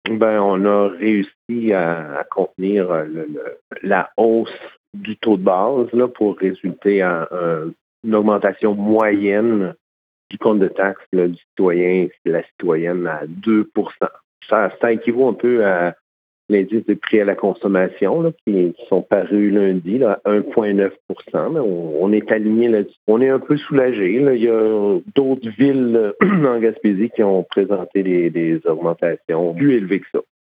Le maire de Percé, Daniel Leboeuf, précise que les contribuables percéens devraient voir une hausse d’environ 2% seulement :